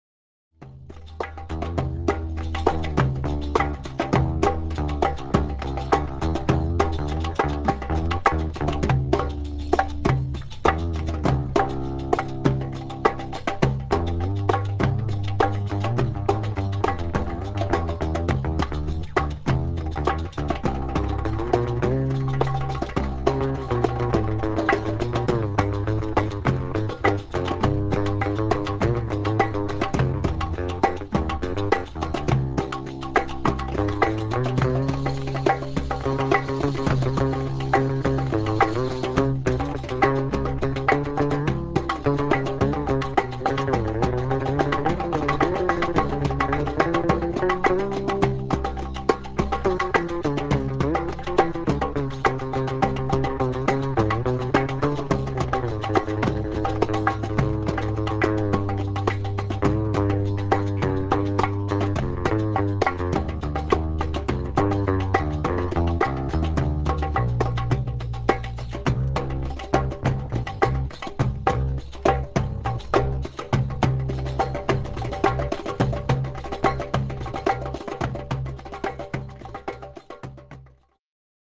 Heavy baladi percussion with bass guitar solo